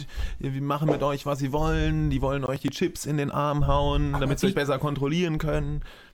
inhaltsensitives Knacken auf Lautsprechern
Anbei ein Ausschnitt aus der Datei, in welchem das Problem sehr gut zu hören ist. Die Originaldatei, also die ohne der Broadcast Behandlung, hat dieses Knacken nicht.